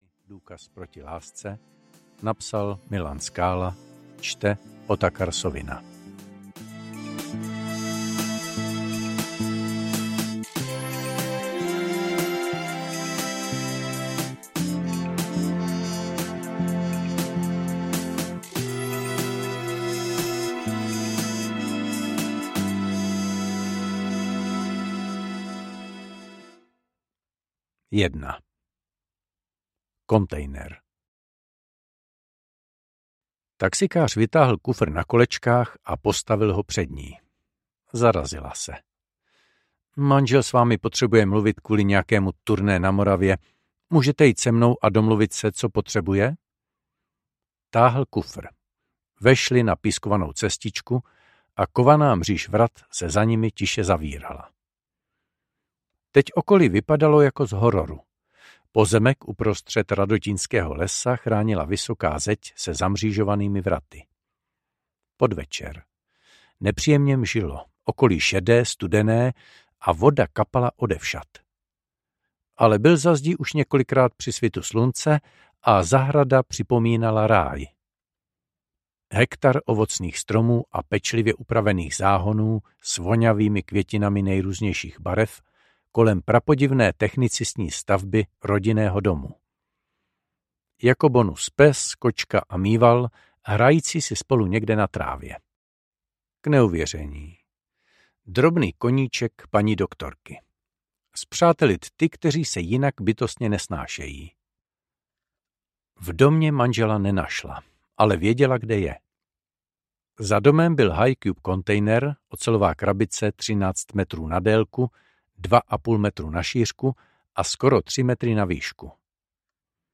Vymazlené vraždy: Důkaz proti lásce audiokniha
Ukázka z knihy